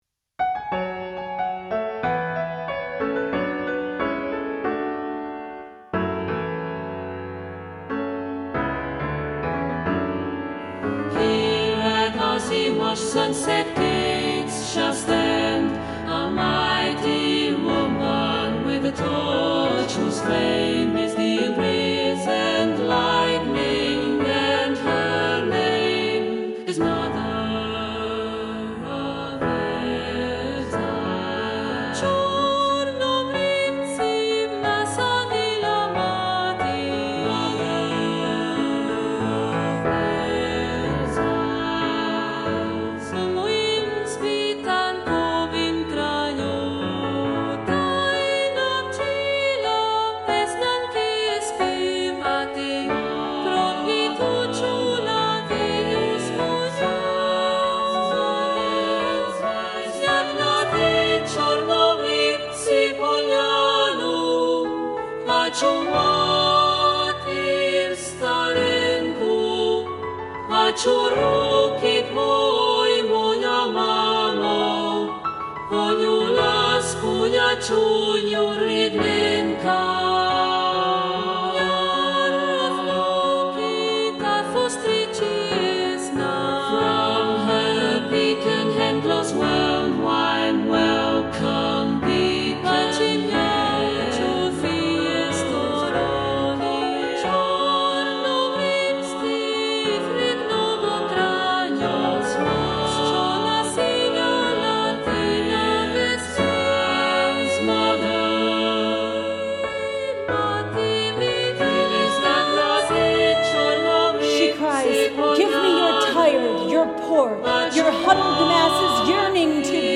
SATB, SSA, SAB